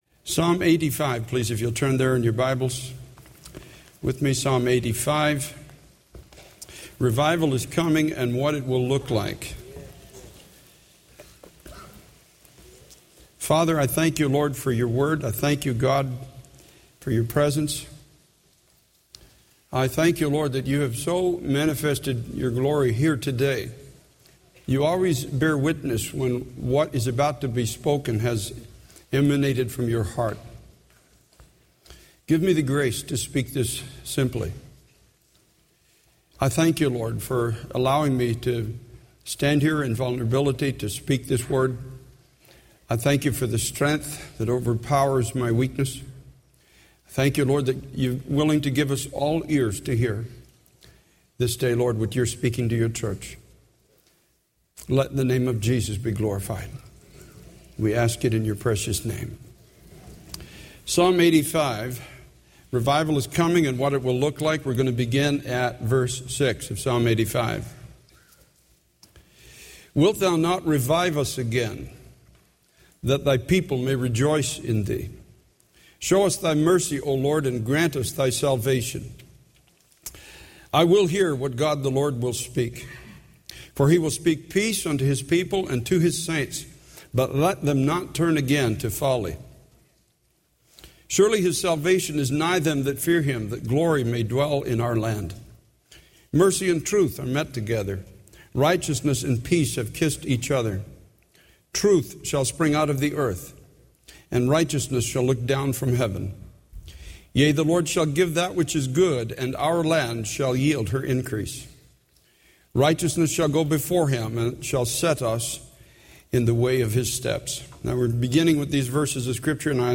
In this sermon, the preacher emphasizes the power of the church and its ability to communicate the wonderful works of God.